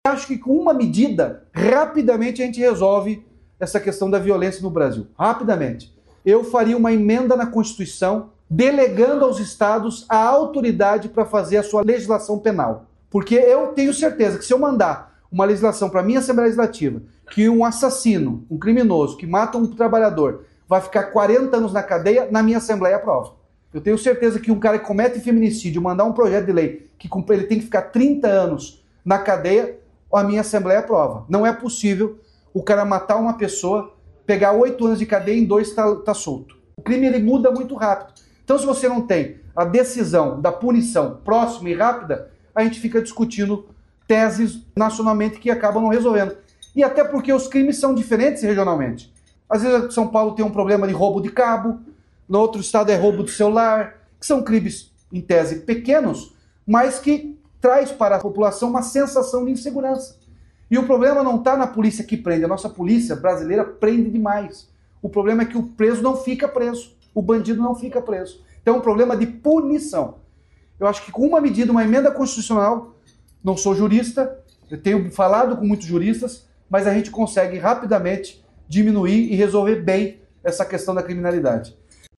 Sonora do governador Ratinho Junior sobre a proposta de autonomia dos estados para aumentar tempo de prisão de criminosos